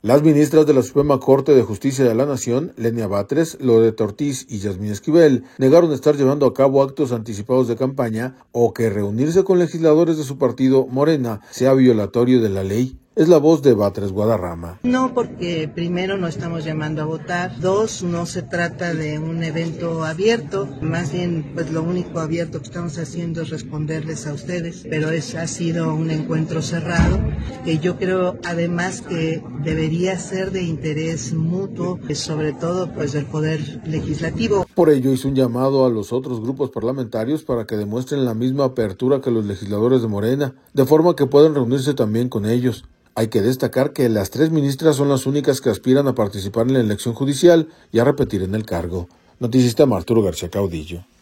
Es la voz de Batres Guadarrama.